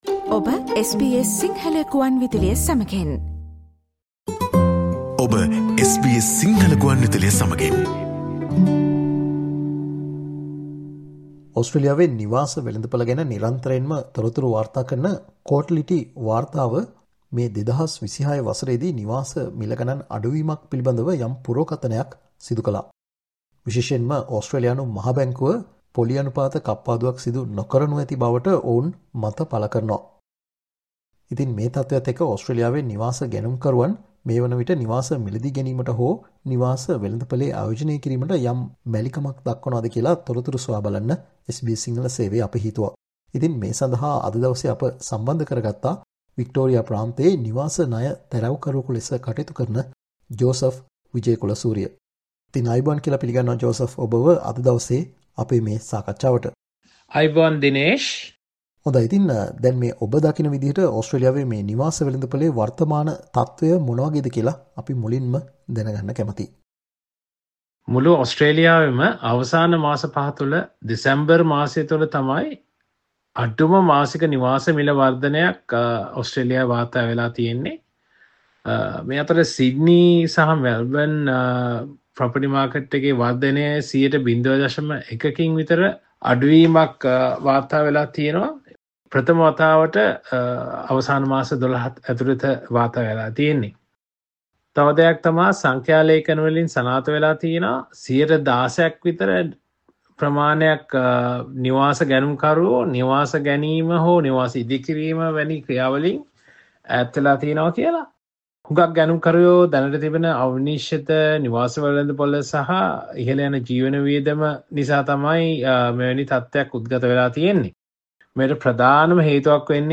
පසුගියදා නිකුත් වුන Cotality වාර්තාවෙන් ඕස්ට්‍රේලියාවේ නිවාස වෙළඳපොල පිළිබඳව යම් පුරෝකථනයන් කර තිබෙනවා. ඕස්ට්‍රේලියානු මහා බැංකුව මේ වසර මුලදී පොලි අනුපාත කප්පාදුවක් ප්‍රකාශයට පත් කිරීම අවිනිශ්චිත බවත් නිවාස වෙළඳපොලේ වර්ධනය අඩු විය හැකි බවටත් යම් අනාවැකි පල වනවා. මේ නිසා වර්තමාන නිවාස වෙළඳපල ගැන කරුණු සොයා බලන්න SBS සිංහල සේවය සිදුකල සාකච්ඡාවට සවන්දෙන්න.